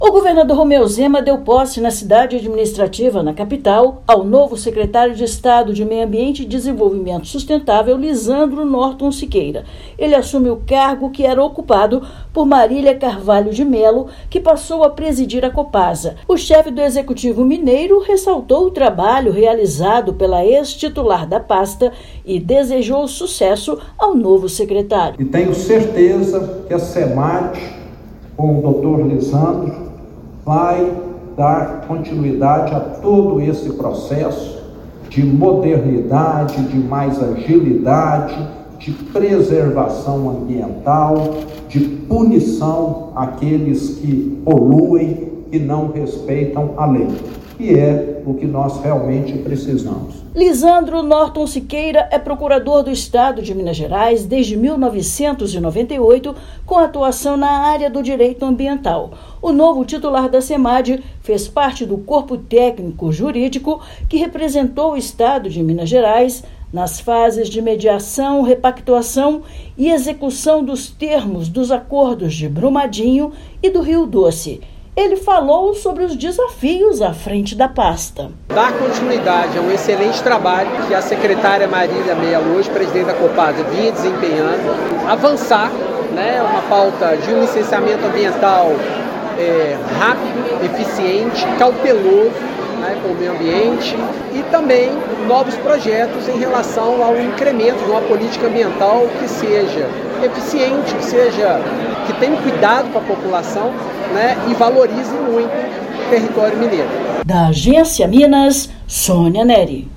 Lyssandro Norton Siqueira integrou a equipe que representou o Estado nos acordos de Brumadinho e do Rio Doce. Ouça matéria de rádio.